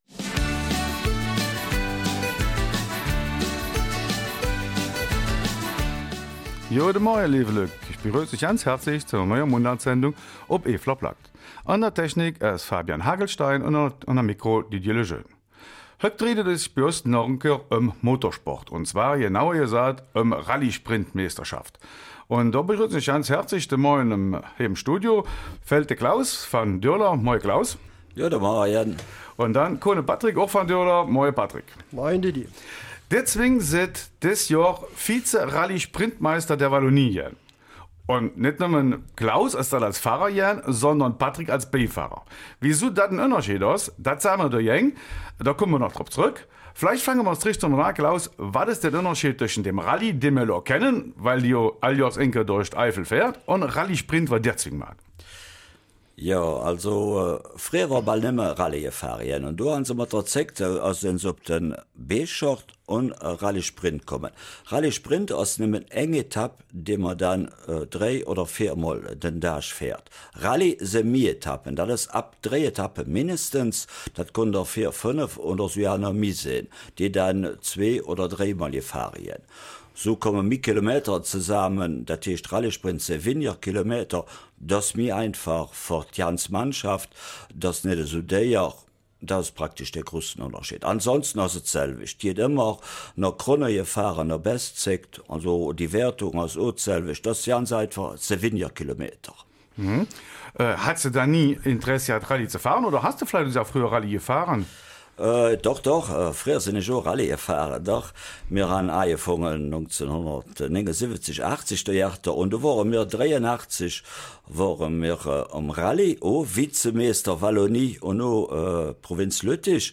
Eifeler Mundart: Eifeler Duo wird Vize-Walloniemeister im Rallye-Sprint